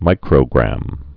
(mīkrō-grăm)